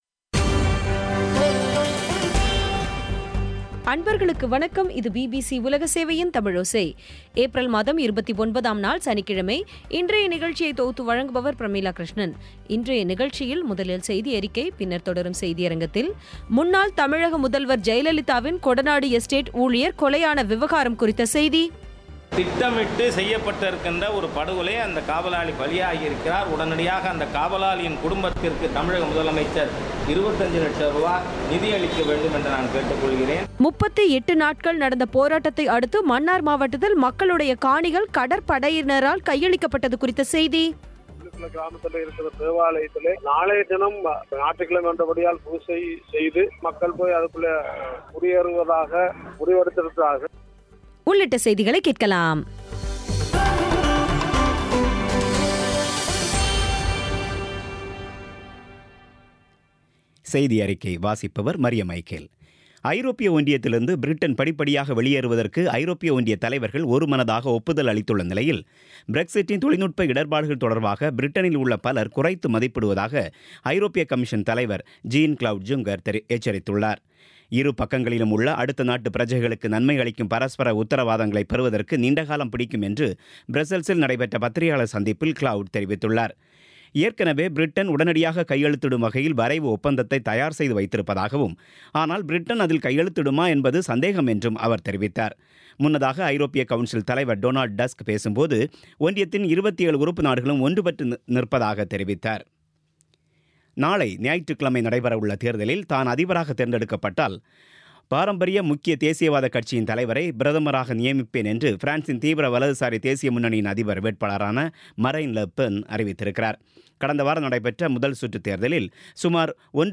இன்றைய நிகழ்ச்சியில் முதலில் செய்தியறிக்கை, பின்னர் தொடரும் செய்தியரங்கில் முன்னாள் தமிழக முதல்வர் ஜெயலலிதாவின் கொடநாடு எஸ்டேட் ஊழியர்கள் கொலையான விவகராம் குறித்த செய்தி 38 நாட்கள் நடந்த போராட்டத்தை அடுத்து, மன்னார் மாவட்டத்தில் மக்களுடைய காணிகள் கடற்படையினரால் கையளிக்கப்பட்டது குறித்த செய்தி உள்ளிட்டவை கேட்கலாம்